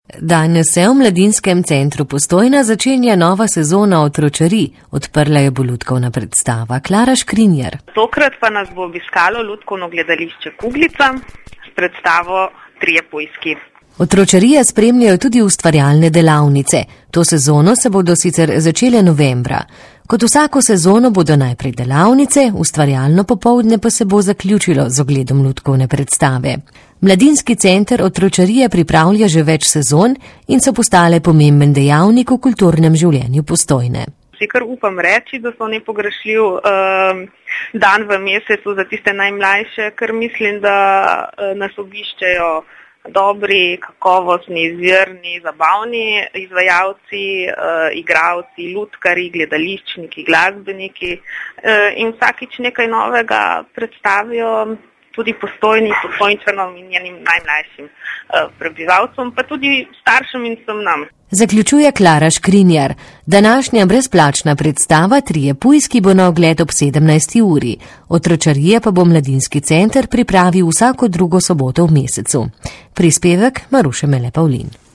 V sredo, 8. oktobra, smo se v Slaščičarni Cukrček spomnili na zmagovalce in jim podelili priznanja. Več v reportaži.